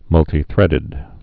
(mŭltē-thrĕdĭd, -tī-)